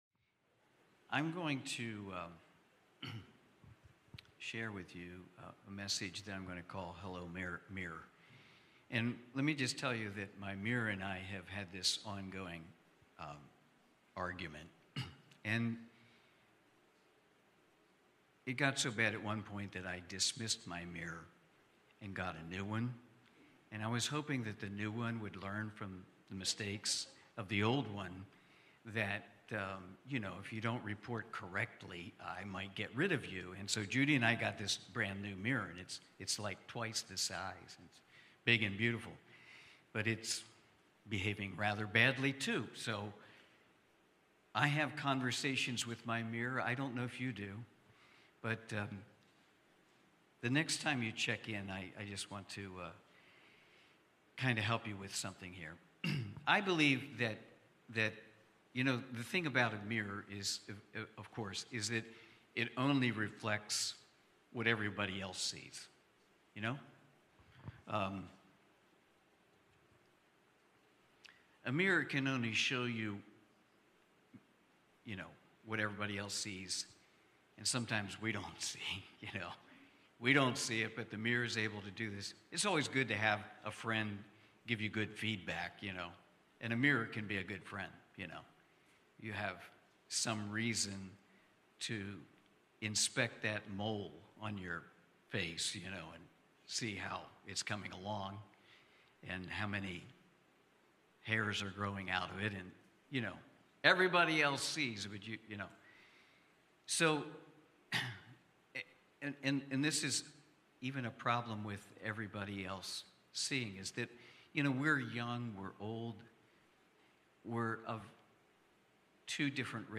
Sunday morning service, livestreamed from Wormleysburg, PA.